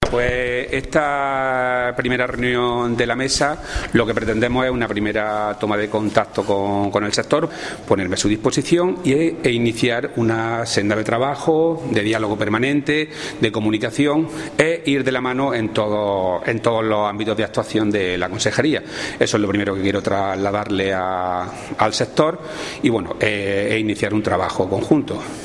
Declaraciones de Rodrigo Sánchez sobre la reunión de la Mesa de Interlocución Agraria